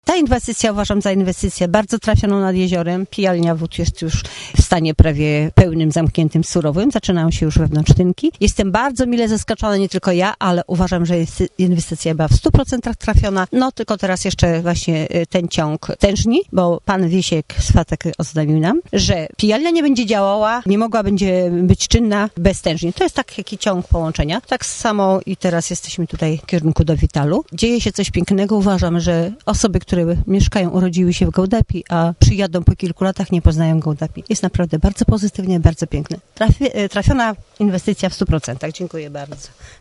mówi Cecylia Stecka, radna Rady Miejskiej w Gołdapi